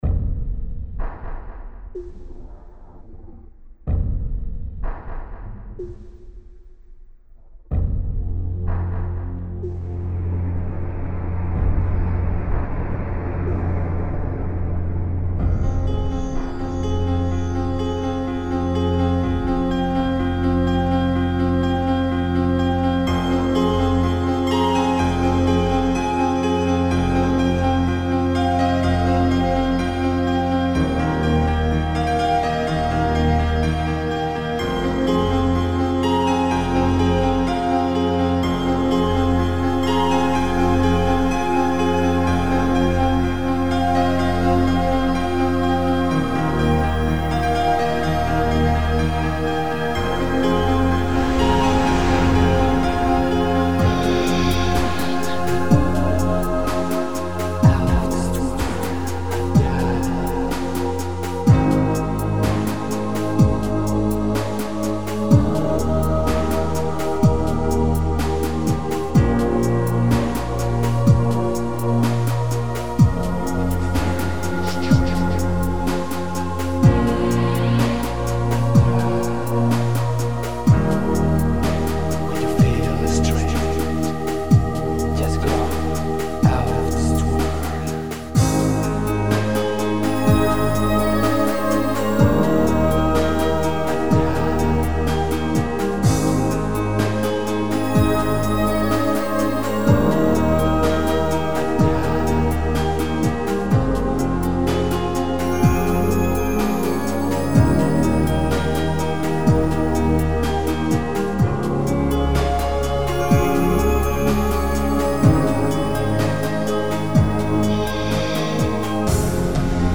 New-age